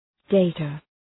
Προφορά
{‘deıtə}